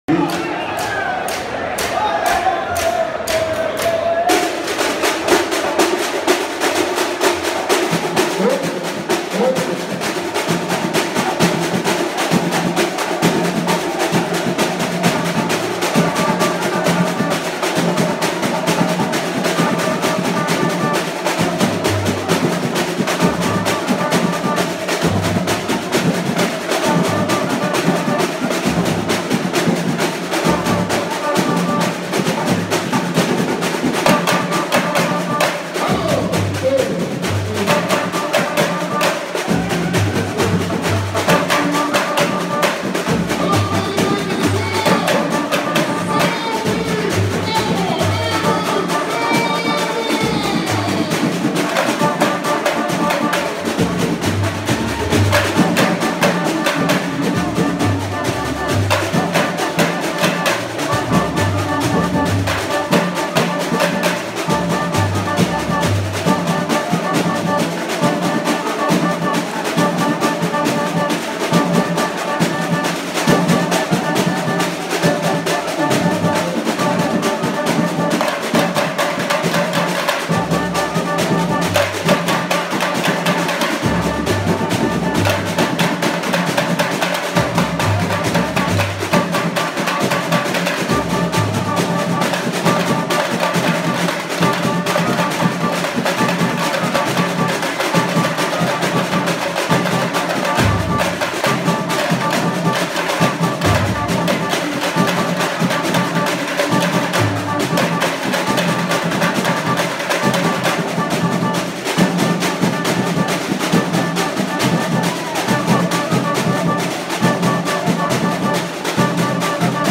SHOW DE BATERIA SALGUEIRO
bateria-do-salgueiro-na-quadra-da-mocidade-alegre.mp3